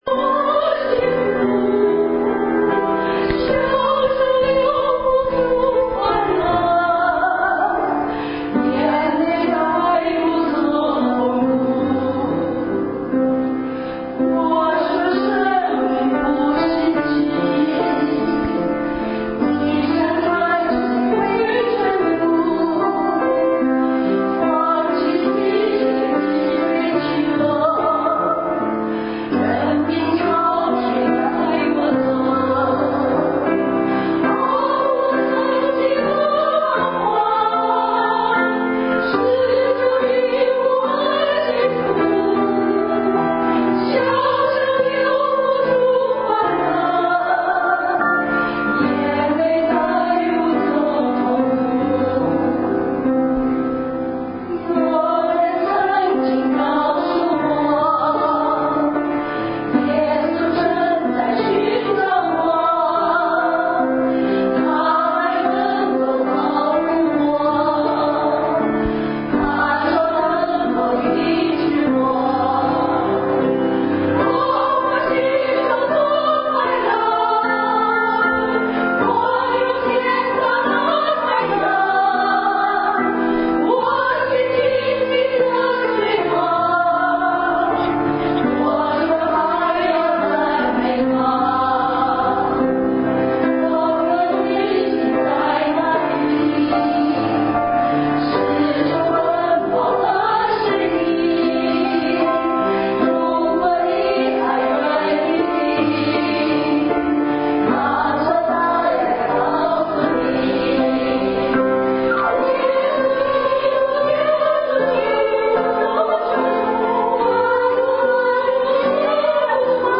In 见证分享